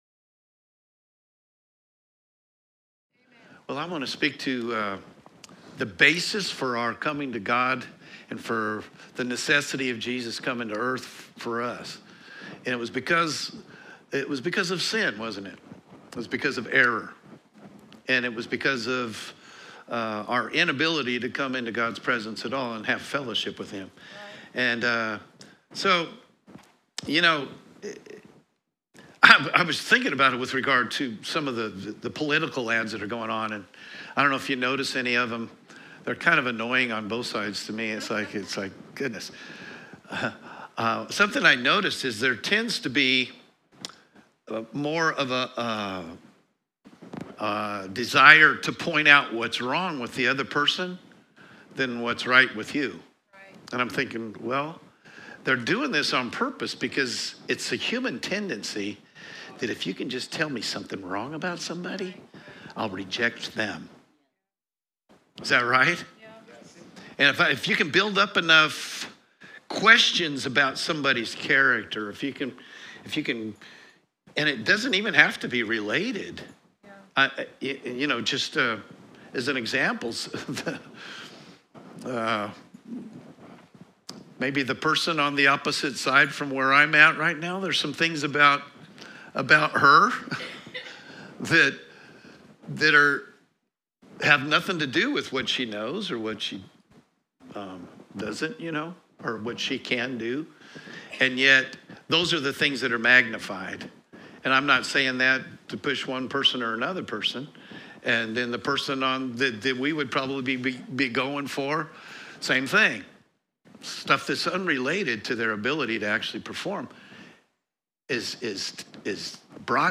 Sermons | New Life Church LH